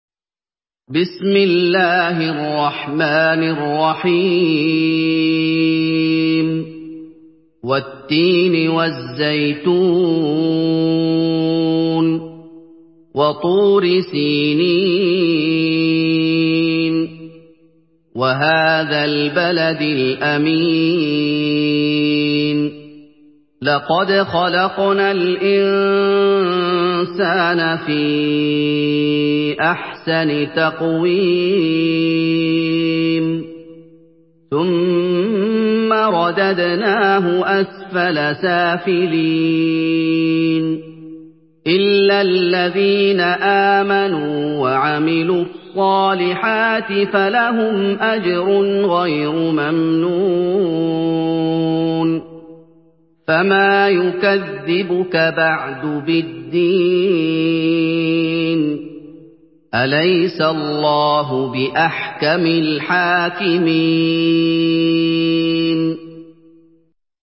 سورة التين MP3 بصوت محمد أيوب برواية حفص
مرتل حفص عن عاصم